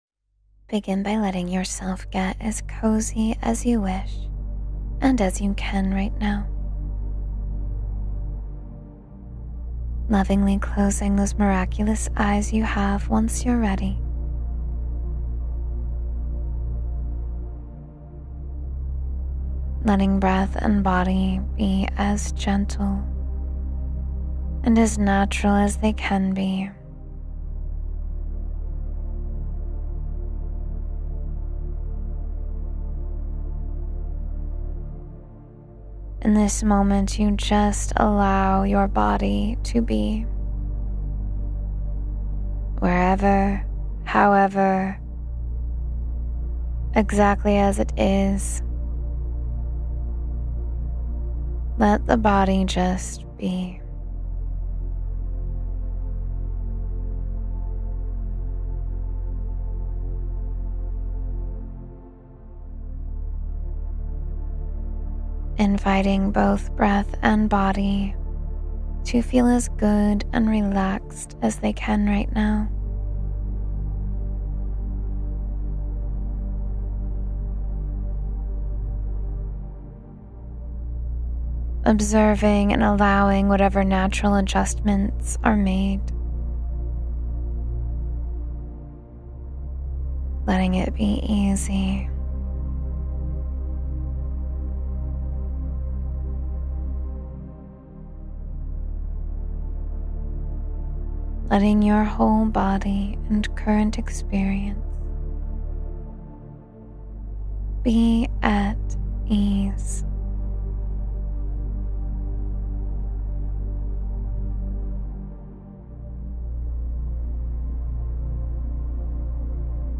Sleep Peacefully—Everything Is Okay — Guided Meditation for Rest and Peace